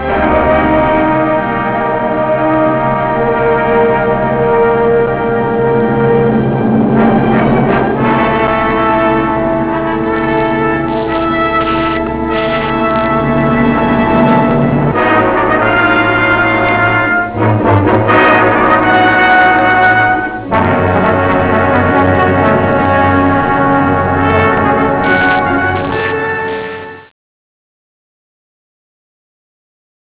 Original track music